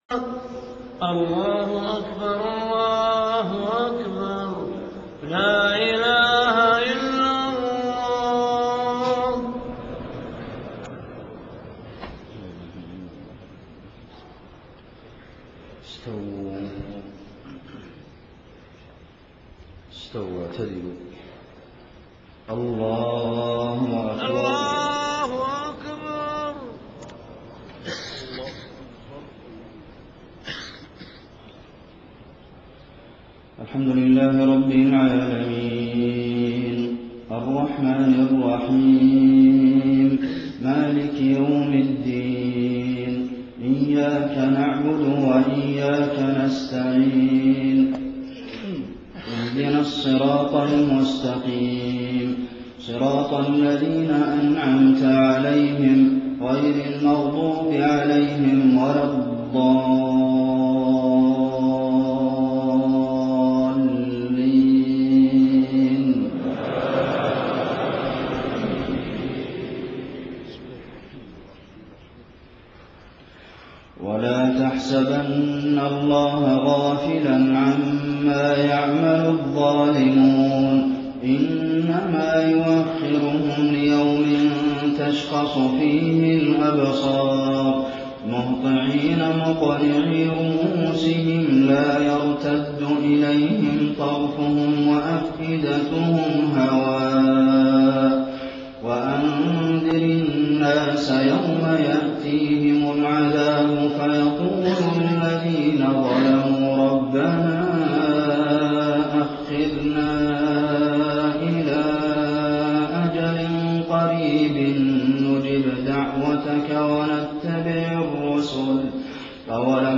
صلاة الجمعة 26 محرم 1430هـ خواتيم سورة إبراهيم 42-52 > 1430 🕌 > الفروض - تلاوات الحرمين